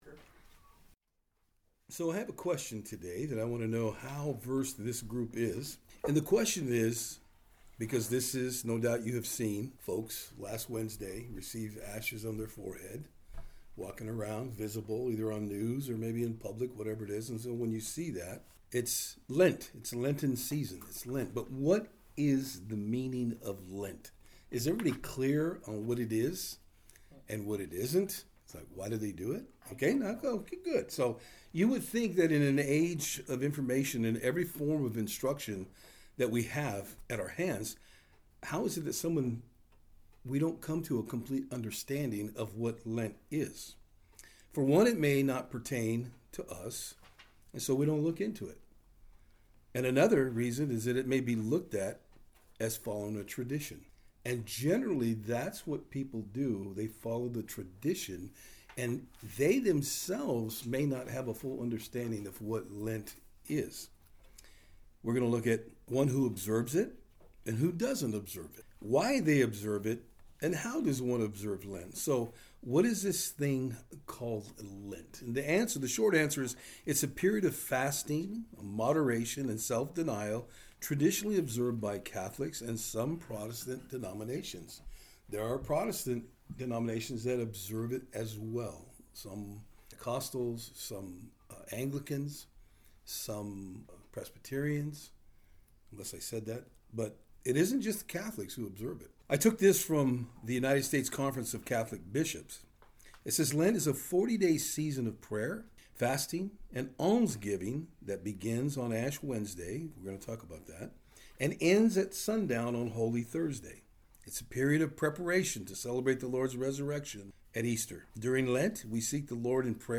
Service Type: Thursday Afternoon